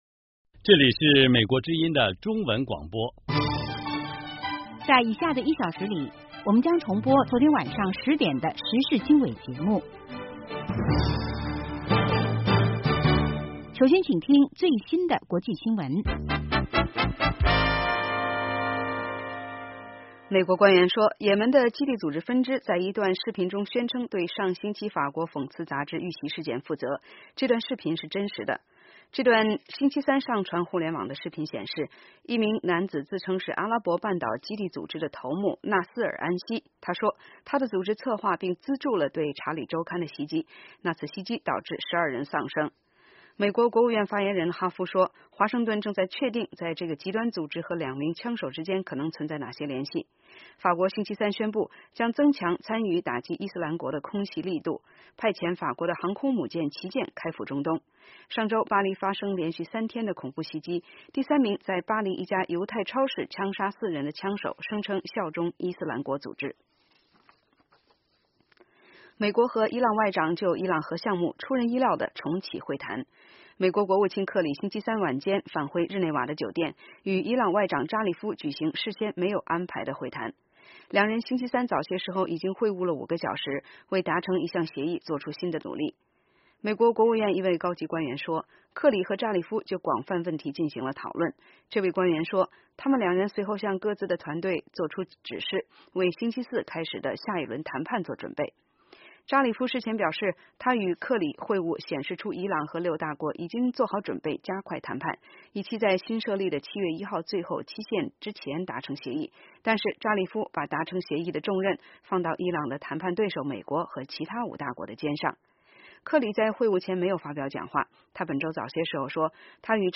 早8-9点广播节目